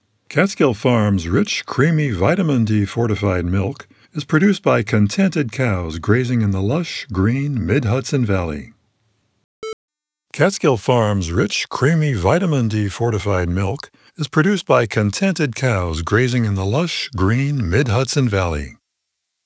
Please review my initial unprocessed .wav recording